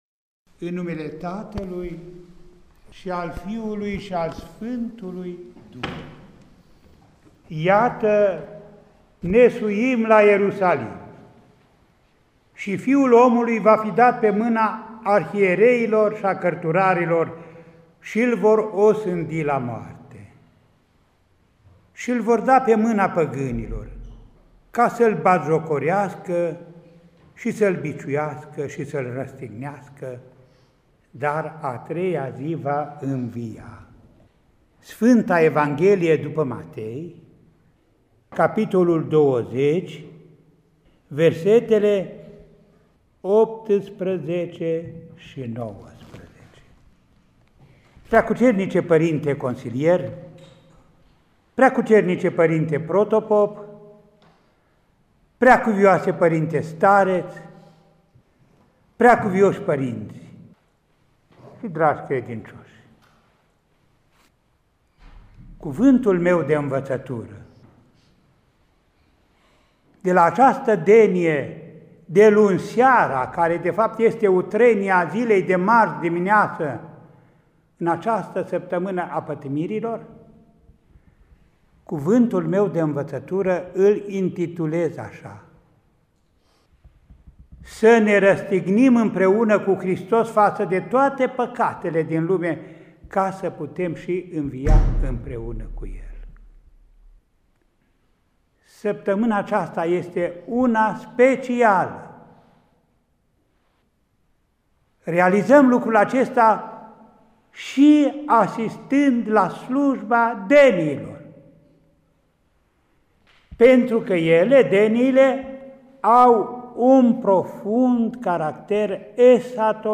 Mitropolitul Andrei – Predică la Denia din Sfânta și Marea Marți – 13 aprilie 2020